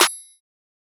MZ Snareclap [Metro #6].wav